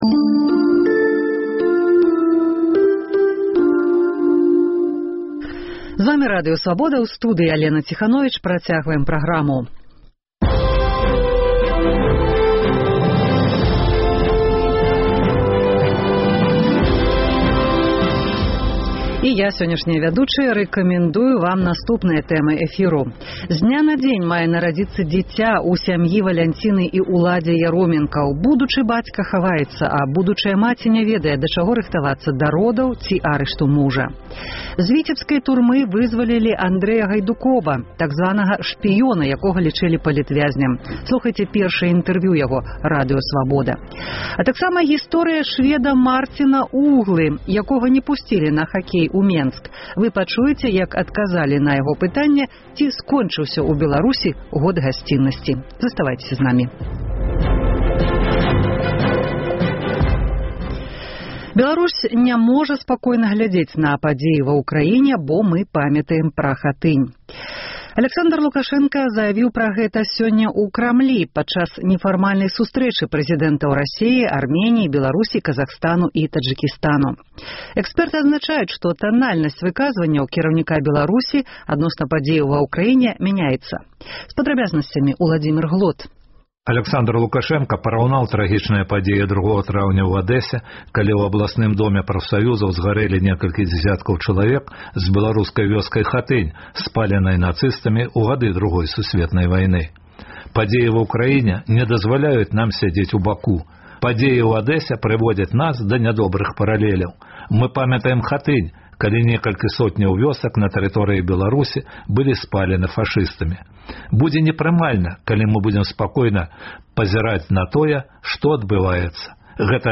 Рэпартаж зь вёскі Шчаткава пад Бабруйскам. Чаму пераможаная Нямеччына жыве лепш, чым краіны былога СССР, які перамог у вайне? Апытаньне ў Гомелі.